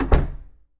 sounds_door_close_03.ogg